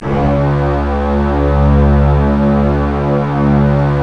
STR STRING0K.wav